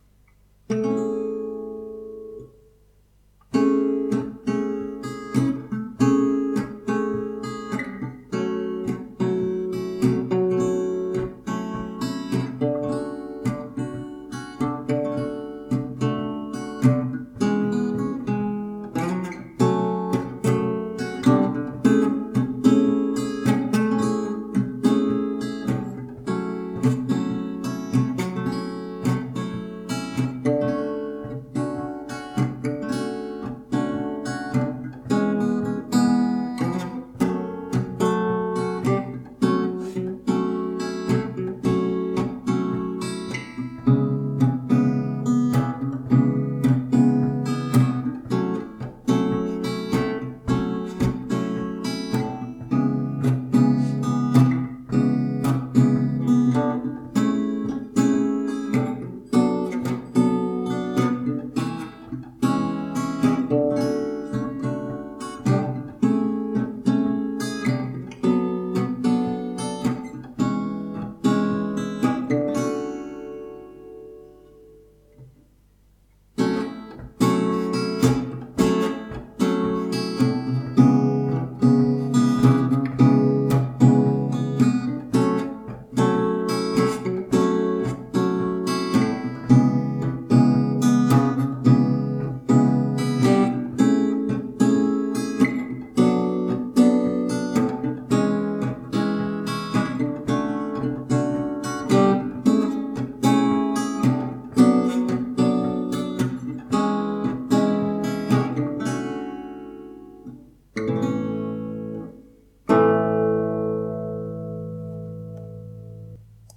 so this is my apology, in a form of guitar composition